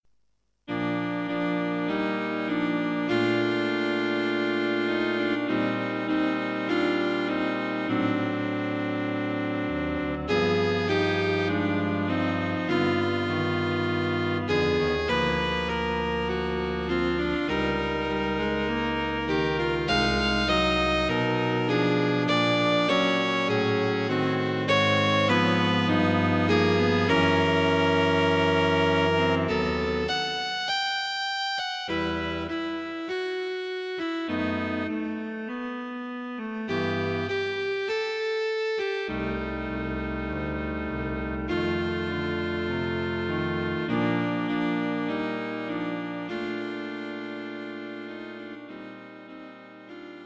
Cello Quartet for Concert performance